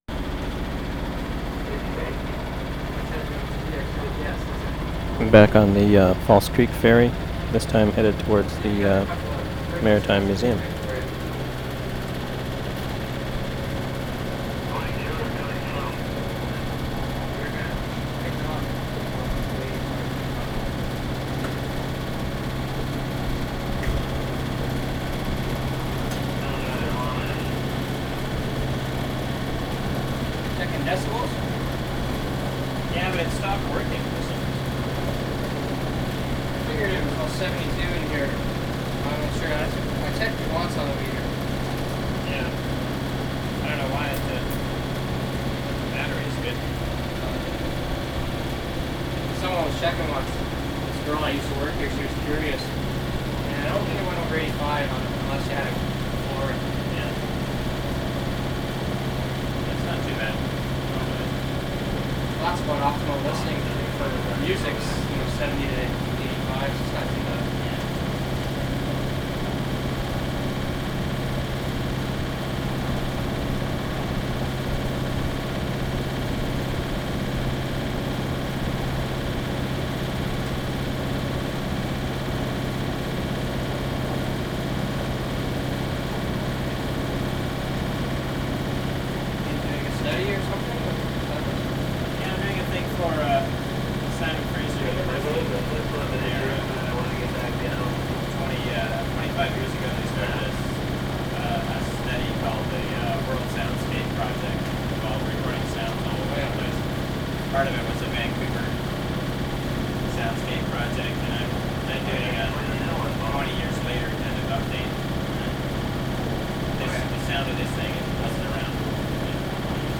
False Creek Ferry from Aquatic Centre to Heritage Harbour 8:16
23. ID, engine, CB radio, conversation with operator regarding dB levels and World Soundscape Project, perspective shifts, docking at 6:52, leaving boat, walking up ramp at 7:26, ID at 8:14